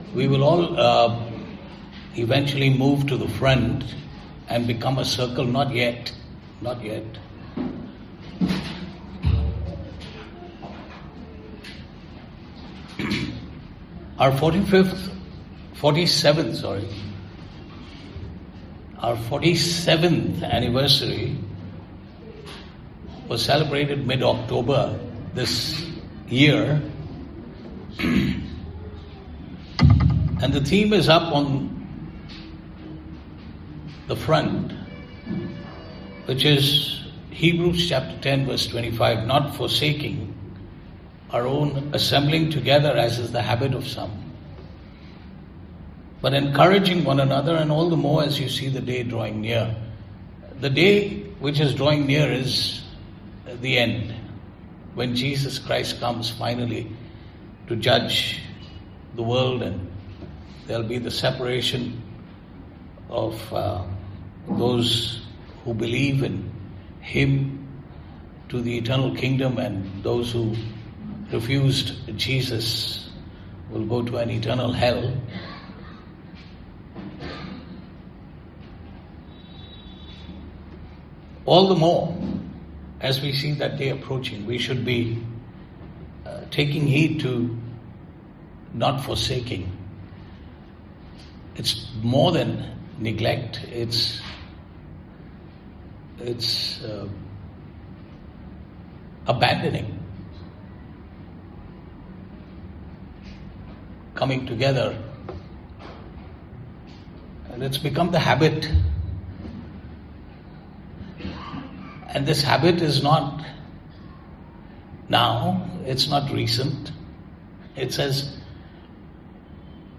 Service Type: Sunday Morning
28thDecSermon.mp3